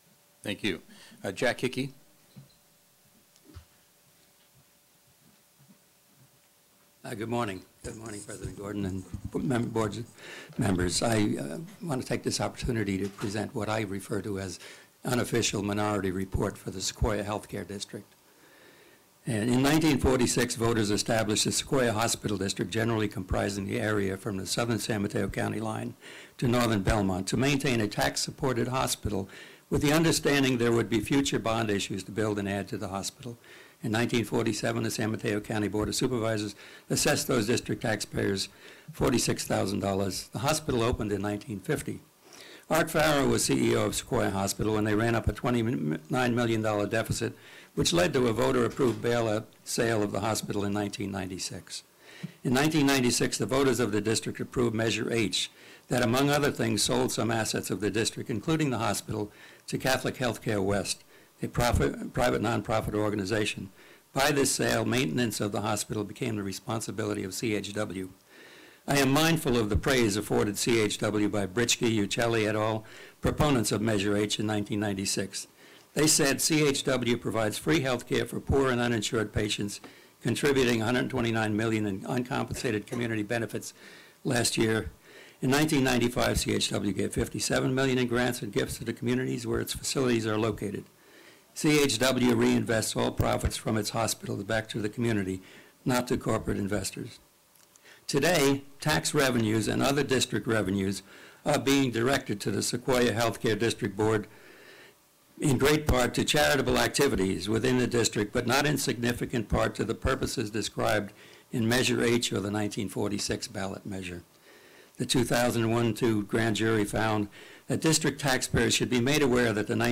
Jack Hickey, Elected Member of the Board
Presented to San Mateo County Board of Supervisors 9/14/2010(